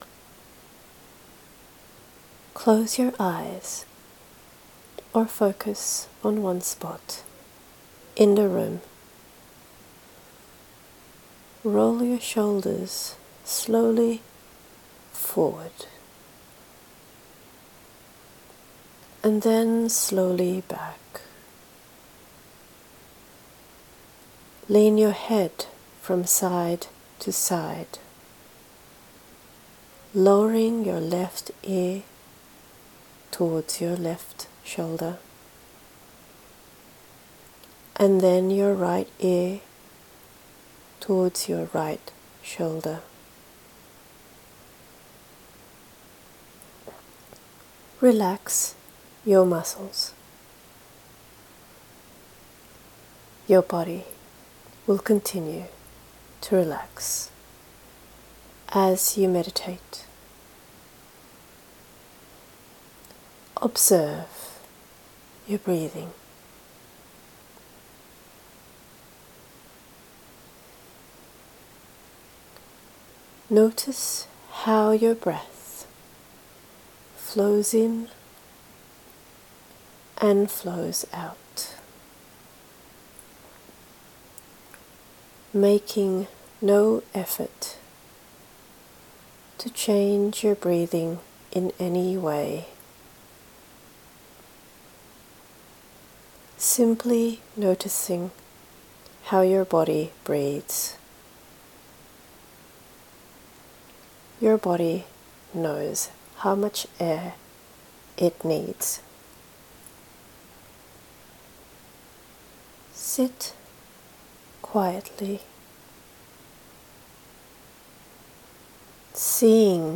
Prism Healing Breathing Meditation
Prism-Healing-Breathing-Meditation.m4a